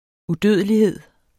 Udtale [ uˈdøˀðəliˌheðˀ ]